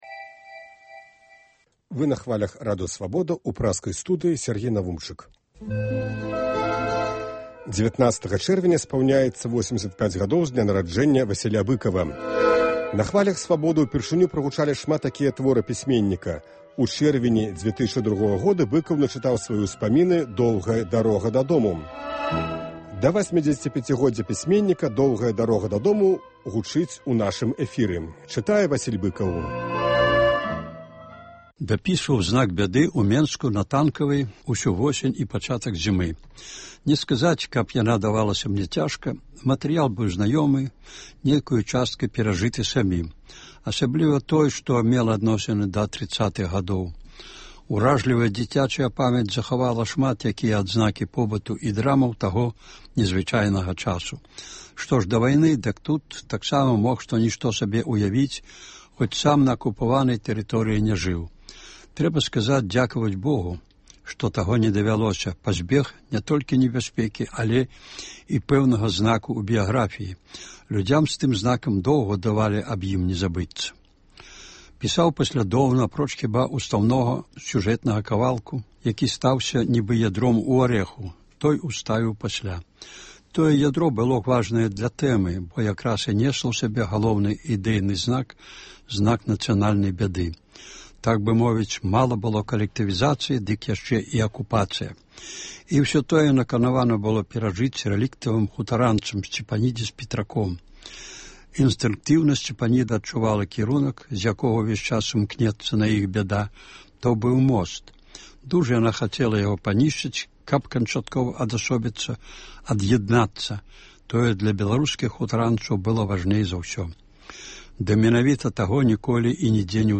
Сёлета ў чэрвені штодня ў нашым эфіры гучыць “Доўгая дарога дадому” ў аўтарскім чытаньні. Сёньня – частка 11-ая.